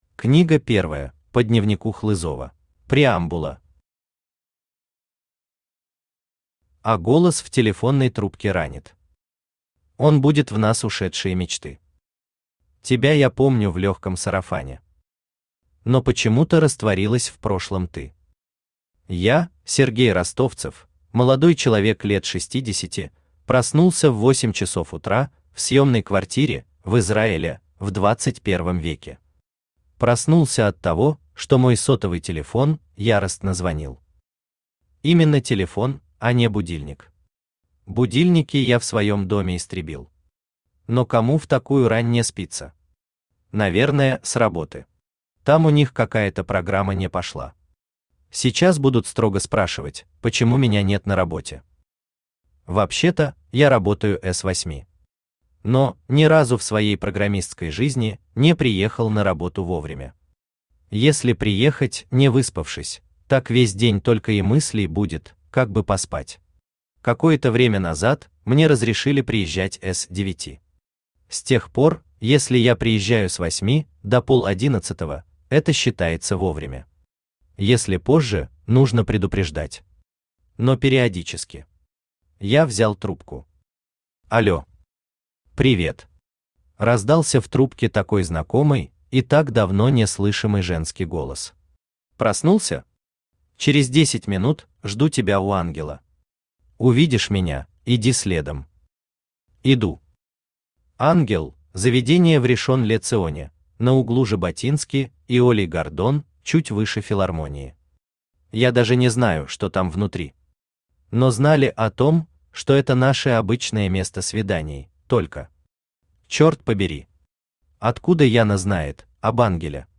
Аудиокнига Дубли | Библиотека аудиокниг
Aудиокнига Дубли Автор Сергей Юрьевич Ростовцев Читает аудиокнигу Авточтец ЛитРес.